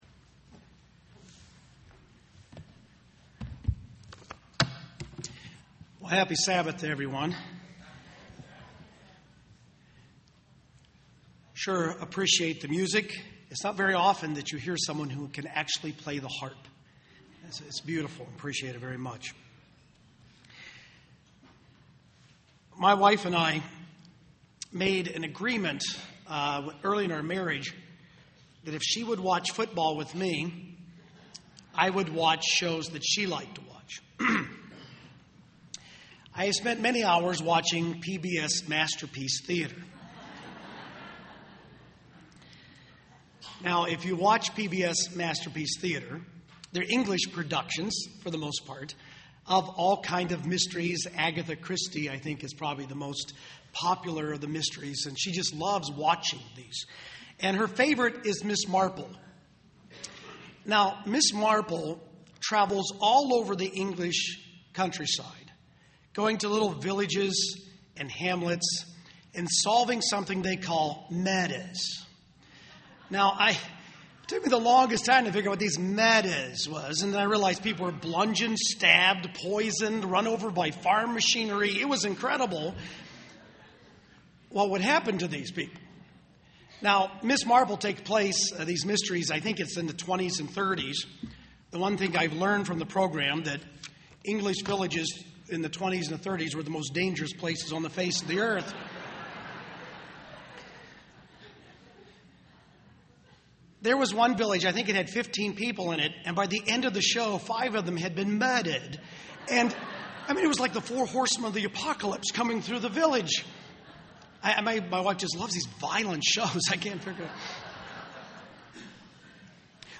This sermon was given at the Kerrville, Texas 2010 Feast site.